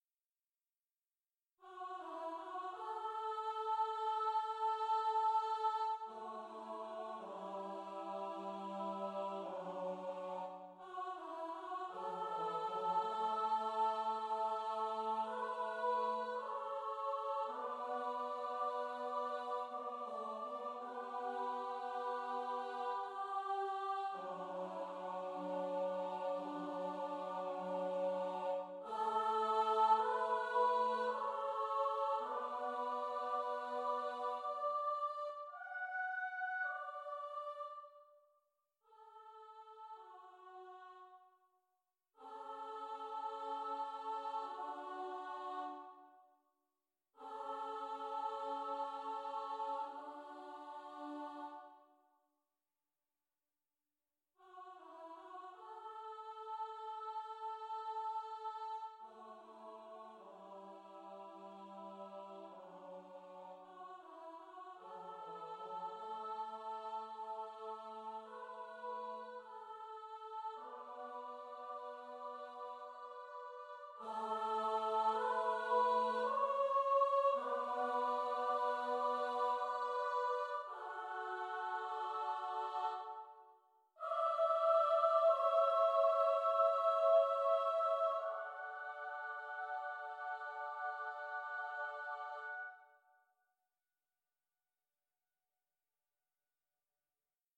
Blessings is a tiny miniature piece, really a gentle prayer, for three part (SAT/B) choir, “for all those dear departed souls” no longer with us – “I wish peace and blessings on you.” It was written on Christmas Eve and Christmas Day, with thoughts of all loved ones departed, everywhere.